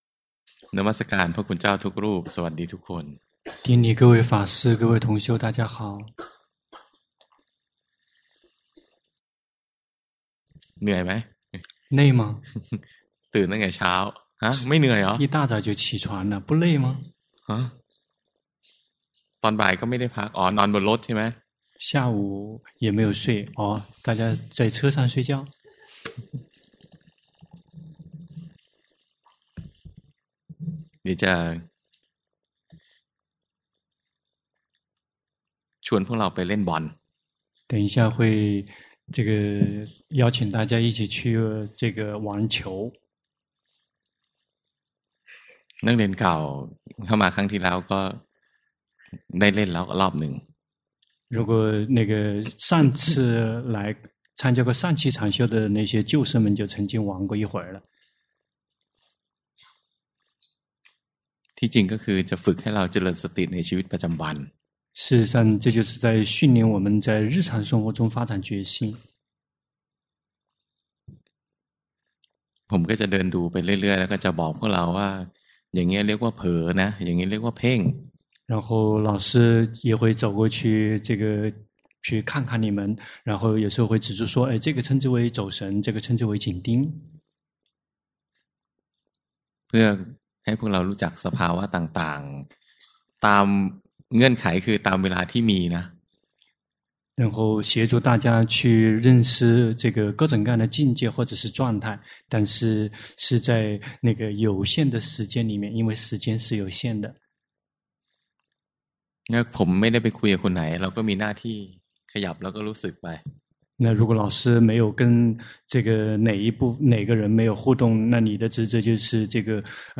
長篇法談｜凡是被觀察的，都不是心 - 靜慮林
第十一屆泰國四念處禪修課程